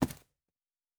Footstep Carpet Running 1_07.wav